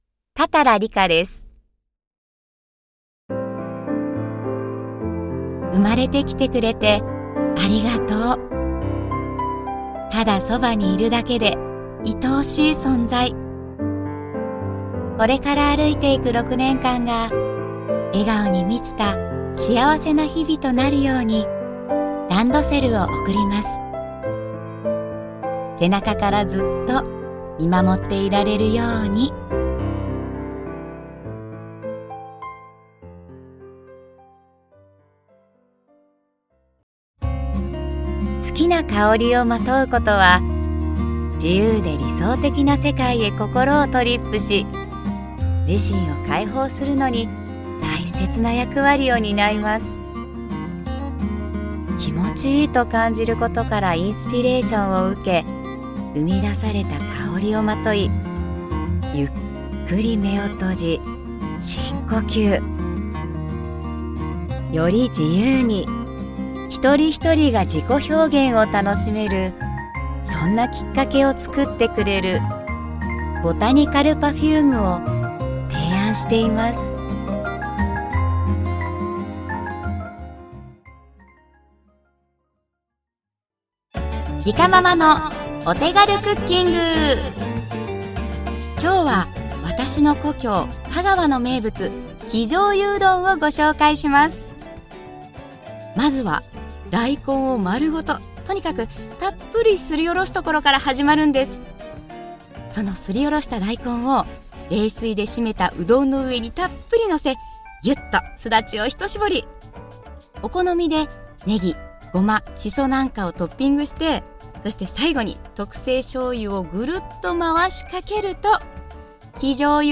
大人の可愛らしい明るい声